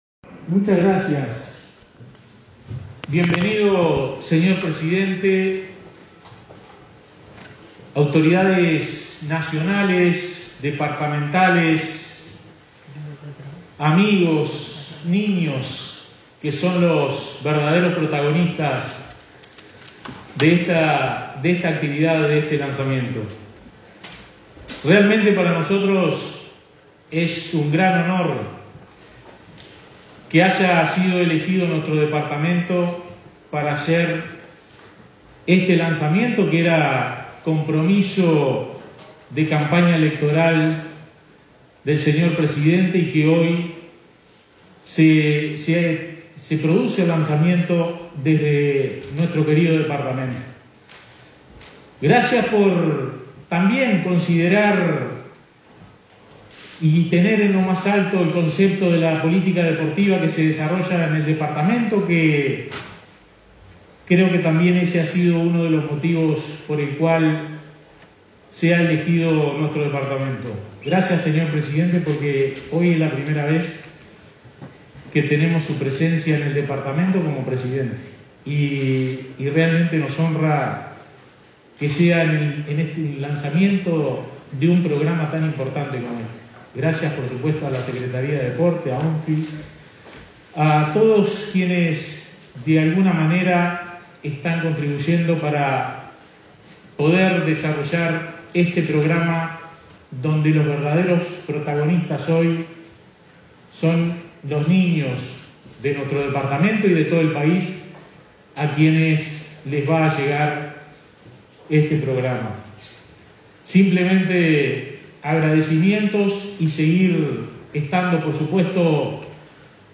Declaraciones del intendente de Flores, Fernando Echeverria
El jefe comunal de Flores participó en el lanzamiento de la Organización Nacional de Deporte Infantil en Trinidad.